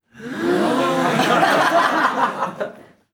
Risas y ovaciones del público